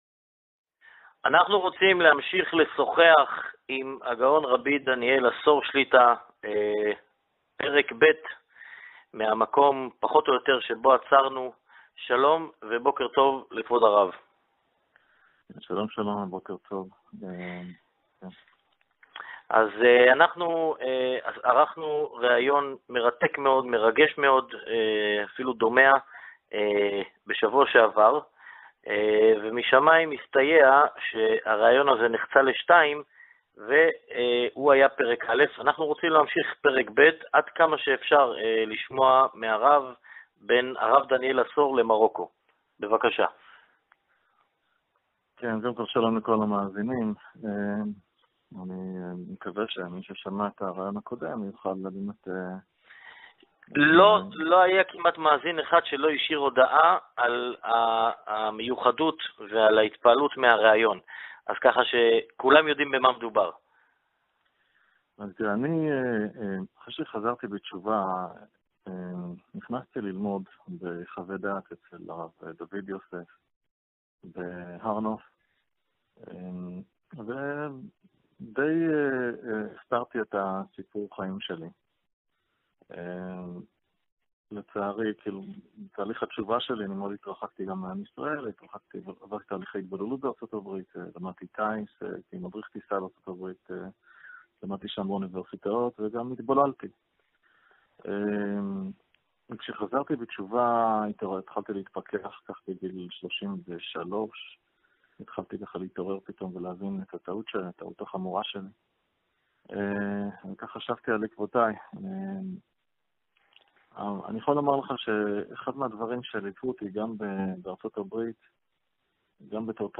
מתראיין לרדיו יהודי מרוקו